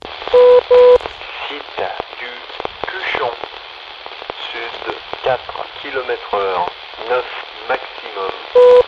De manière à attirer l'attention des pilotes avant la diffusion du message, il est précédé de deux tonalités simples à 500 Hz. Une troisième tonalité conclut le message.